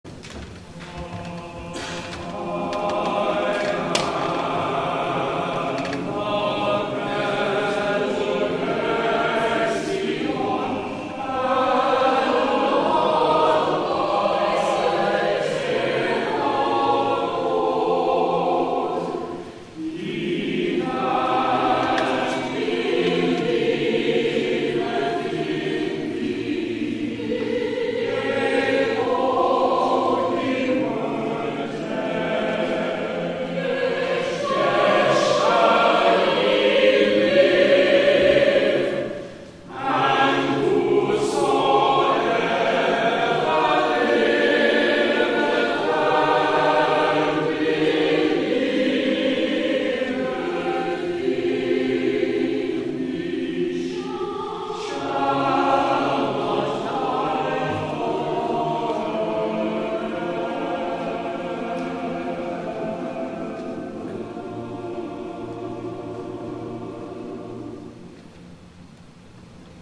*THE CHORAL RESPONSE
It is set simply without much polyphony so that the text, and its message of hope that Jesus gave to Lazarus' sister and still gives to us, remains clear.